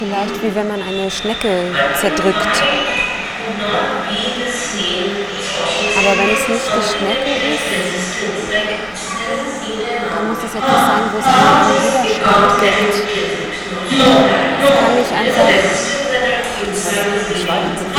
Hallo liebe Forum-User, ich bin als Audiobearbeiter in einem kleinen Verlag für Audiomedien tätig und bräuchte mal Eure sachkundige Hilfe für ein spezielles Problem: Es geht um eine zweisprachige (deutsch/englisch) Seminaraufnahme mit Simultanübersetzung, mit folgendem Aufnahmesetting (Mono...